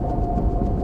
ht-locomotive-engine.ogg